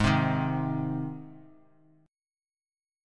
G# Chord
Listen to G# strummed